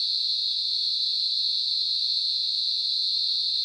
cicadas_night_loop_03.wav